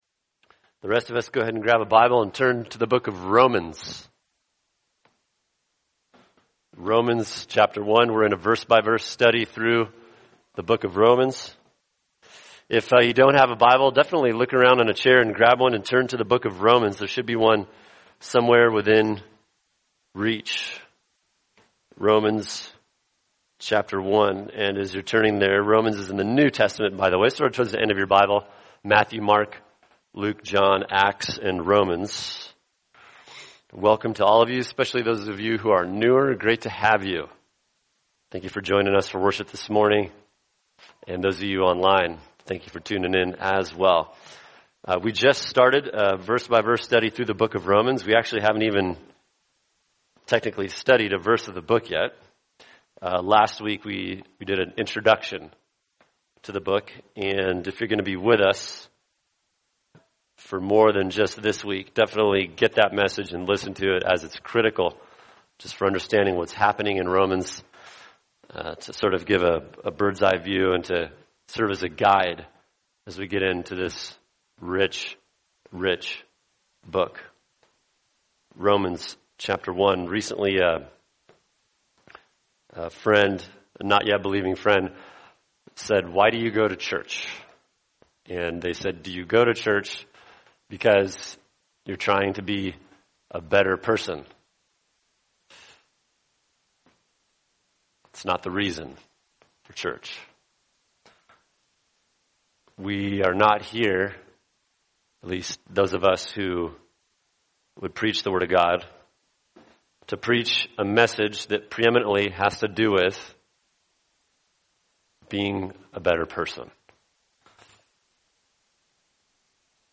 [sermon] Romans 1:1-7 The Highlights of the Gospel | Cornerstone Church - Jackson Hole